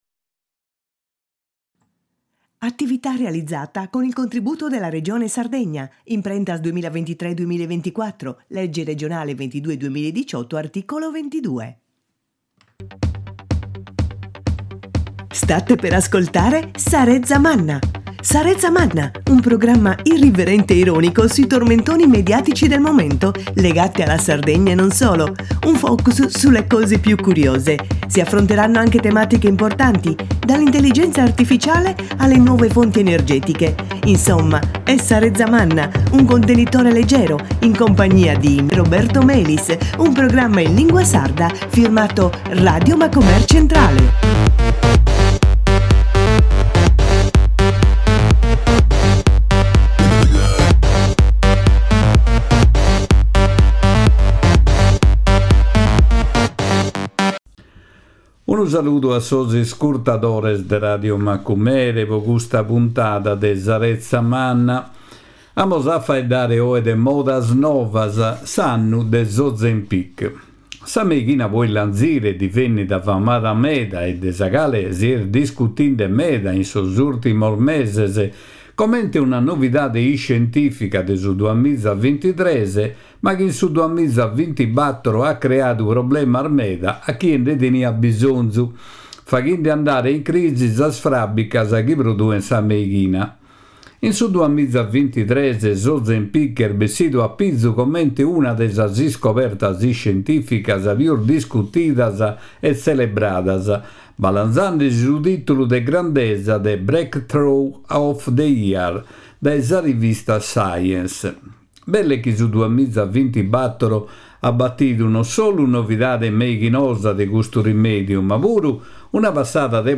Modas novas, s’annu de s’Ozempic: sa meighina po illanzire. A sighire: Semus in Sedilo cun su sindigu Sarbadore Pes e sa vice M.Antonietta Meloni: Sedilo e sos monumentos, s’Ardia, sa situatzione sotziale, e sas manifestassiones de su mese de santuaini (Zenias de Sedilo e Ichnos), Sa retza e sos problemas po sos zovanos